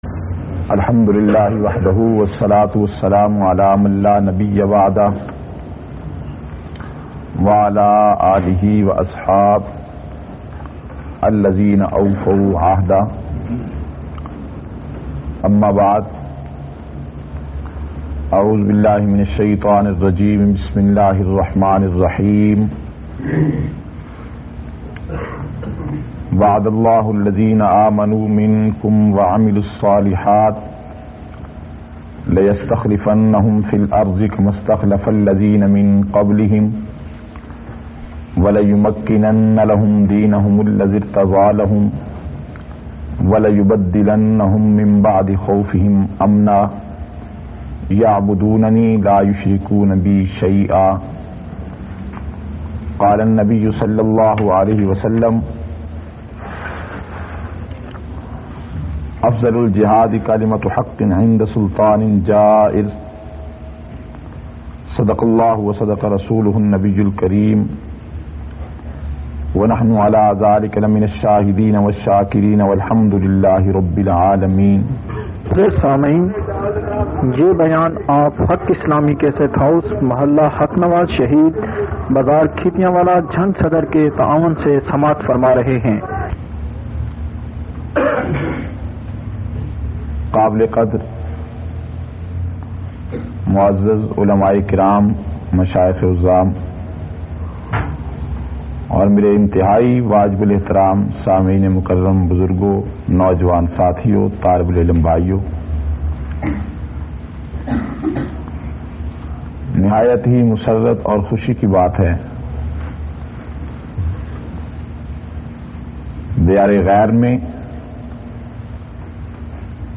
365- Terbiyati Nashist Jeddah Saudi Arab Khitab.mp3